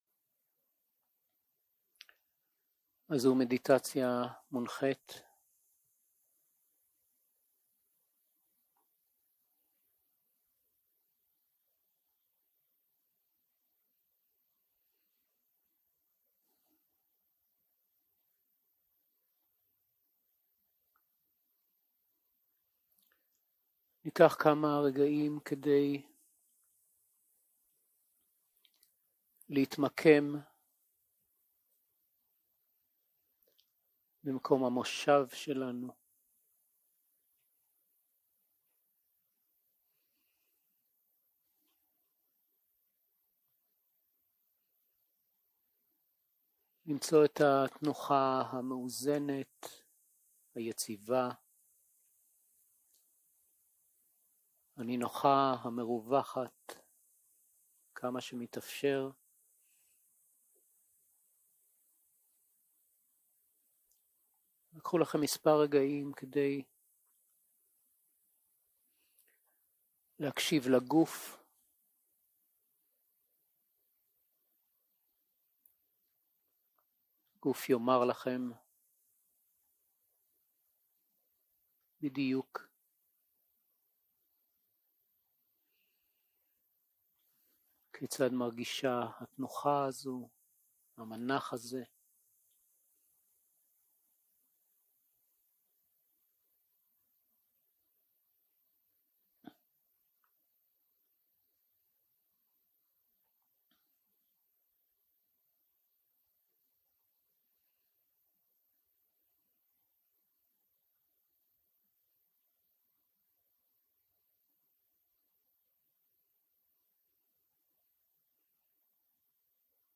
יום 3 - הקלטה 6 - צהרים - מדיטציה מונחית - ודאנה
Dharma type: Guided meditation שפת ההקלטה